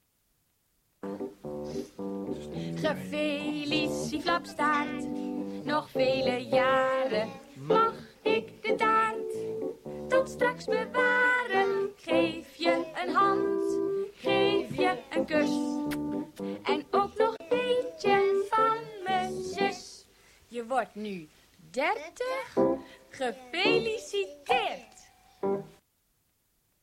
fragment radio-uitzending  /  of klik hier